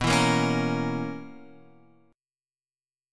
B Major 7th Suspended 2nd Suspended 4th